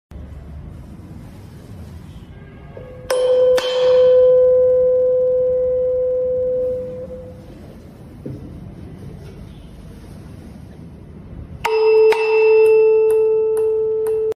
Mp3 Sound Effect When the first box vibrates, it sends sound waves (oscillations of air) through the room at 412 Hz.
The box starts to vibrate strongly, without ever being touched.